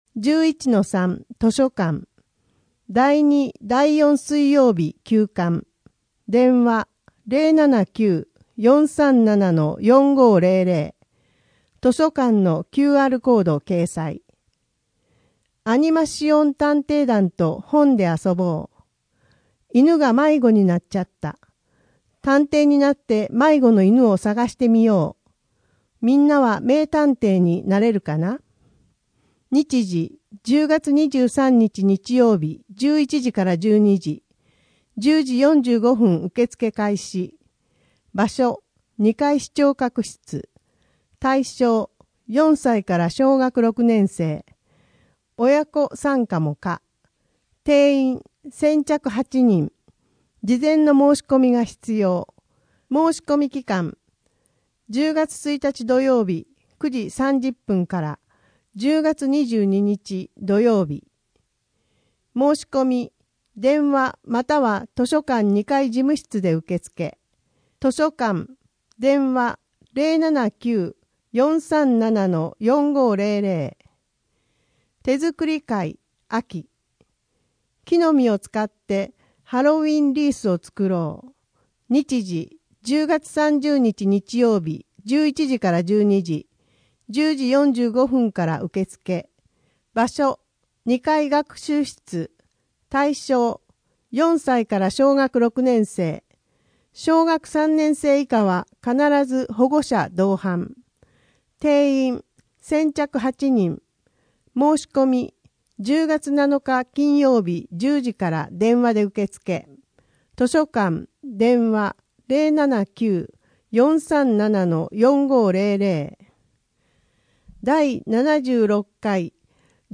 声の「広報はりま」10月号
声の「広報はりま」はボランティアグループ「のぎく」のご協力により作成されています。